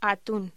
Locución: Atún